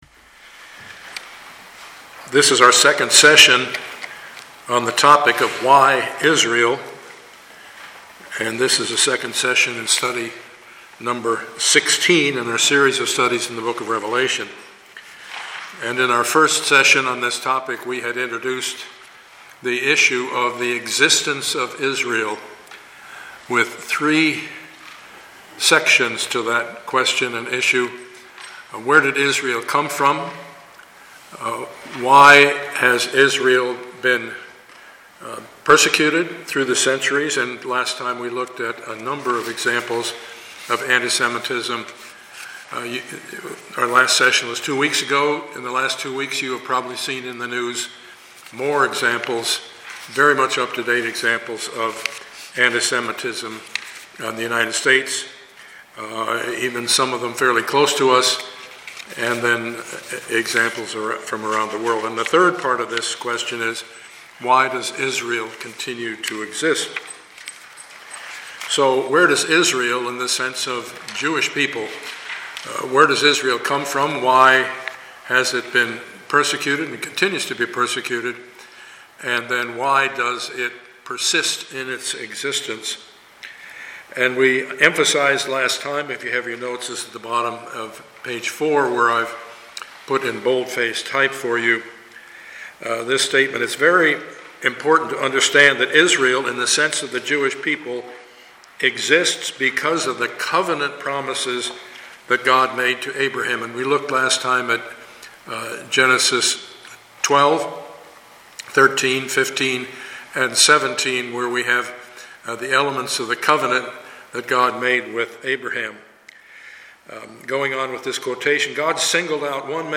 Studies in the Book of Revelation Passage: Revelation 11:15-12:17 Service Type: Sunday morning « God Glorified in the Resurrection of Christ